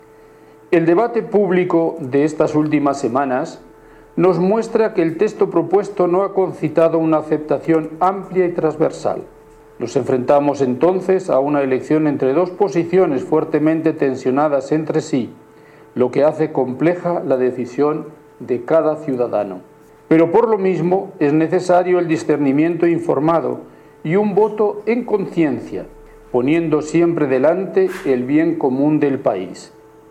En la declaración leída por el Cardenal Celestino Aós, Presidente de la Conferencia Episcopal de Chile, los obispos reconocen que el borrador constitucional divide al país.